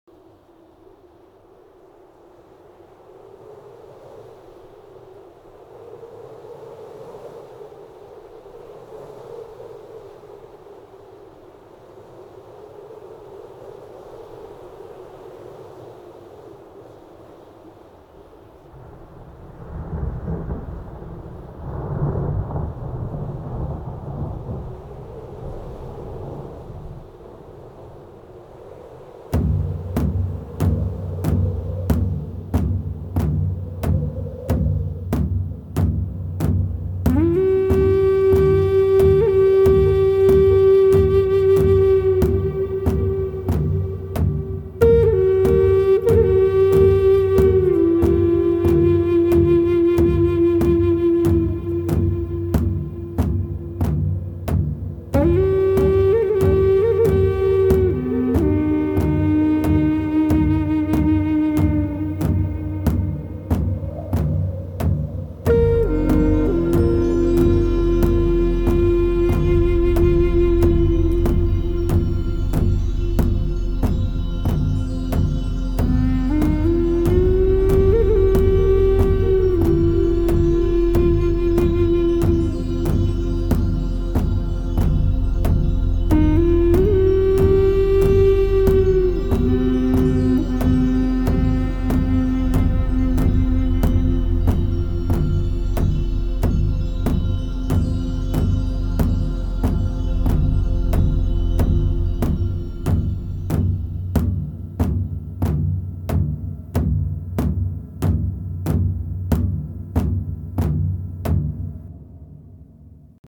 印第安鼓，笛子，阿帕奇部落（Apache)的颂词以及对精神世界的表达
都融合进了清新，现代和美妙的音乐之中。
与雄鹰一起在天空中翱翔，伴随着鼓的节奏翩翩起舞